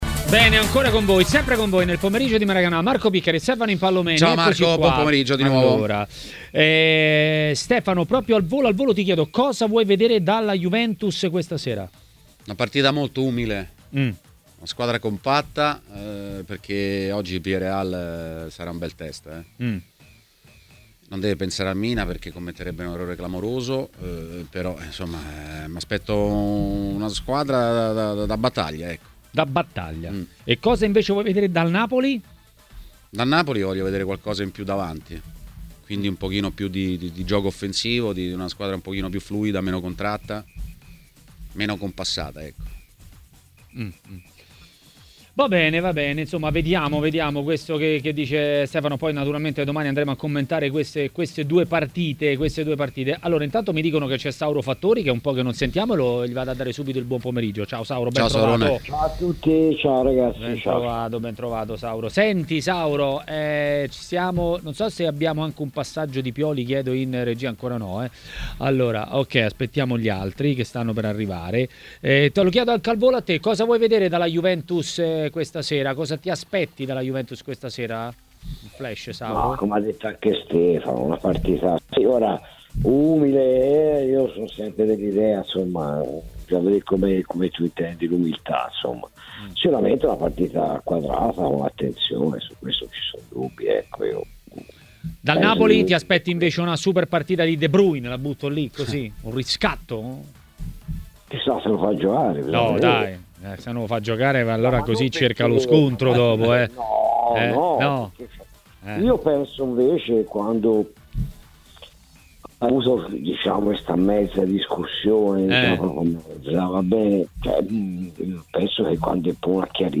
Ospite di Maracanà, trasmissione di TMW Radio, è stato l'ex calciatore Roberto Galbiati.